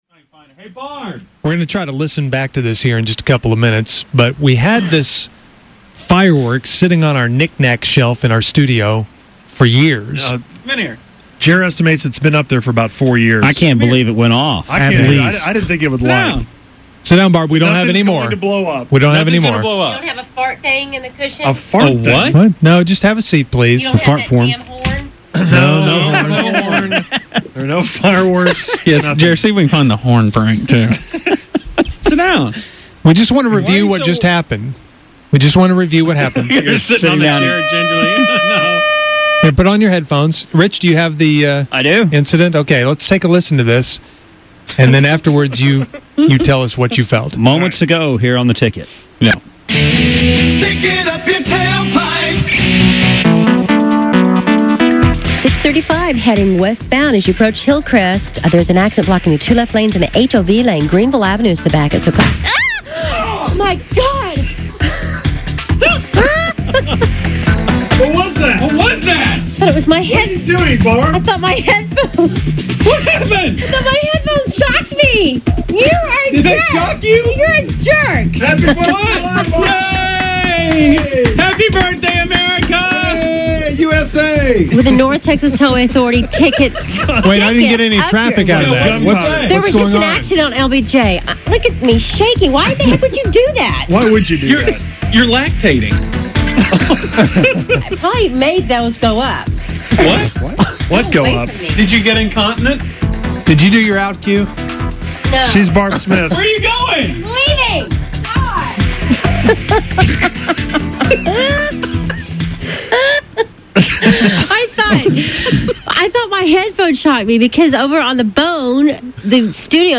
While she was doing her traffic report
firecracker
with an air horn